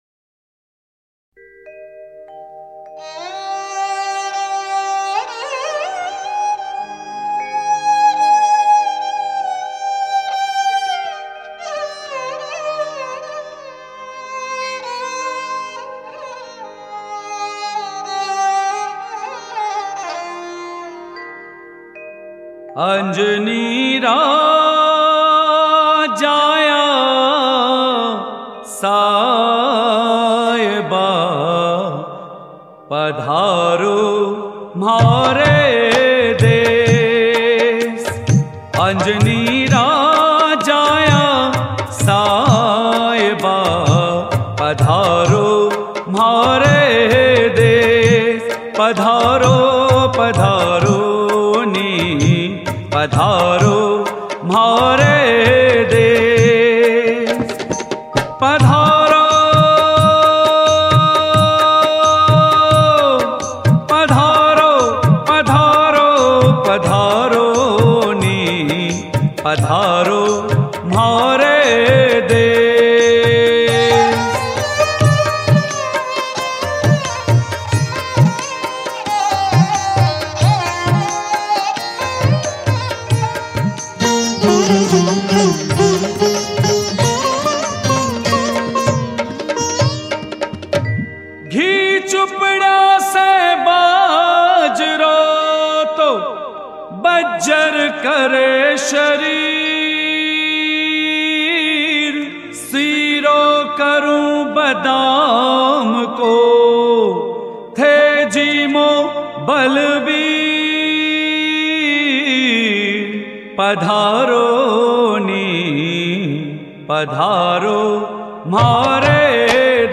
Balaji Bhajan